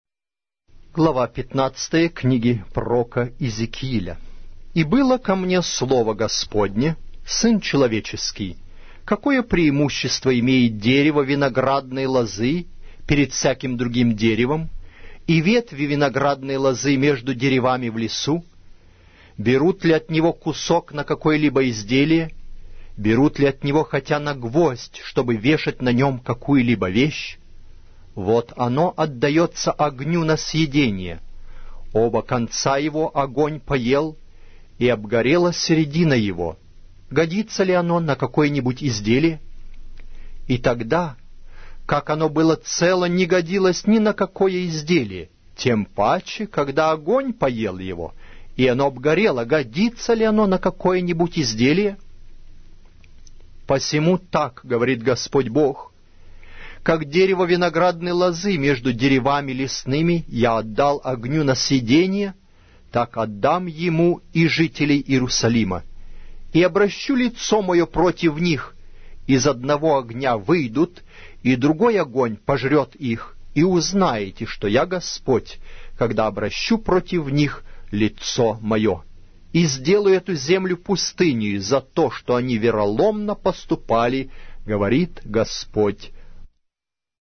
Аудиокнига: Пророк Иезекииль